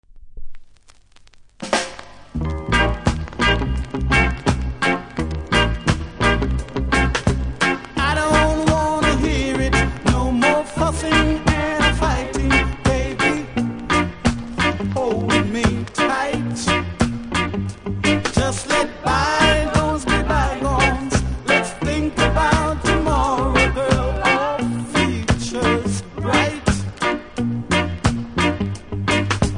キズ多めですがノイズはそれほどもないので試聴で確認下さい。